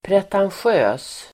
Ladda ner uttalet
Folkets service: pretentiösa pretentiös adjektiv, pretentious Uttal: [pretansj'ö:s] Böjningar: pretentiöst, pretentiösa Synonymer: anspråksfull Definition: anspråksfull, fordrande pretentiösa adjektiv, pretentious